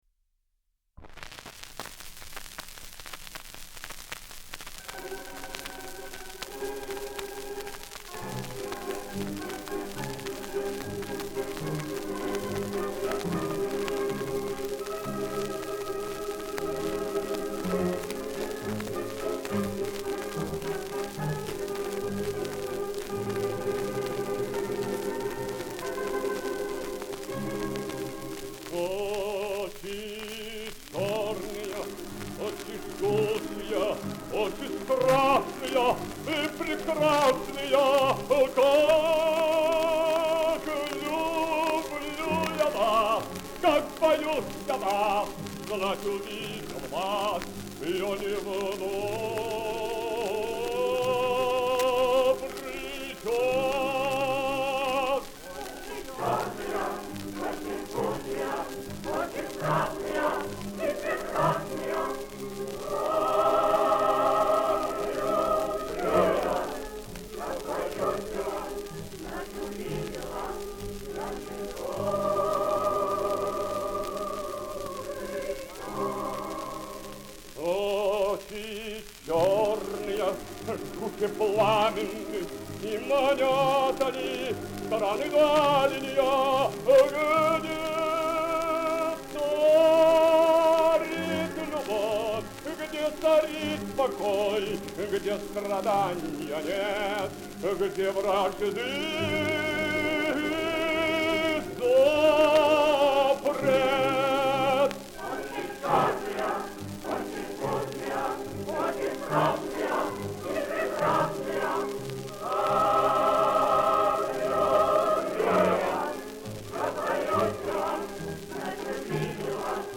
русской хоровой (духовной и народной) музыки
Романс
оркестр балалаек.